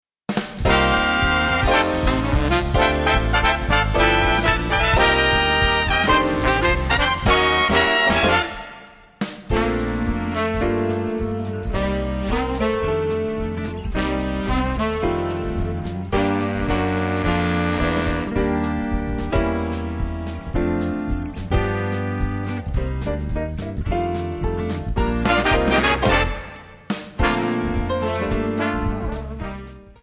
Bb
MPEG 1 Layer 3 (Stereo)
Backing track Karaoke
Pop, Oldies, Duets, 1950s